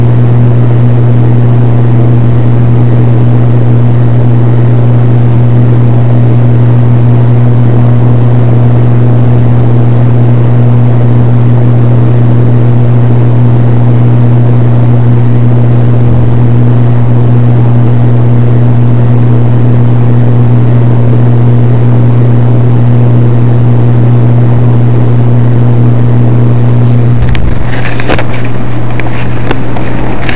Another example creating a buzz by placing the computer on top of something hollow.
jack-and-jill-hum-9-jan-2015.mp3